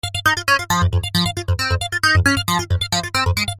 吉他 " CRAZYFLANGE5
描述：吉他，低音，循环
Tag: 低音 循环 吉他